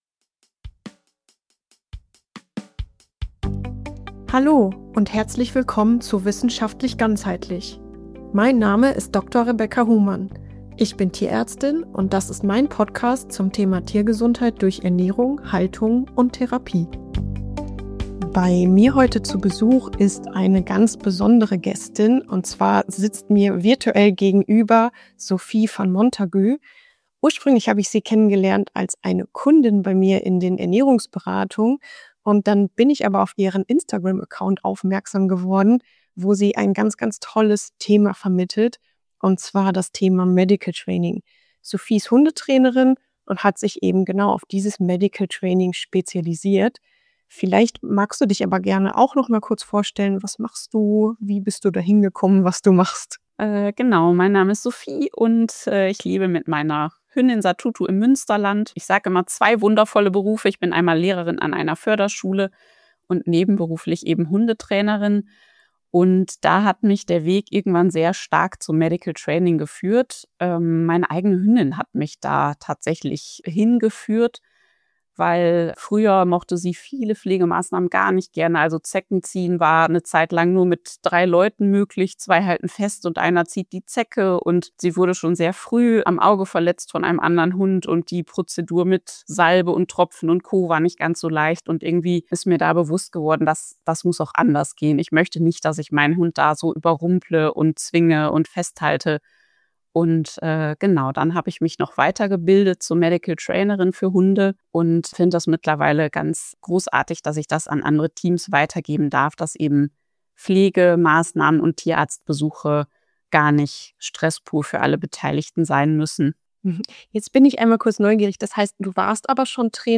In dieser Folge spreche ich mit Hundetrainerin